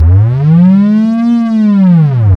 SGLBASS  4-R.wav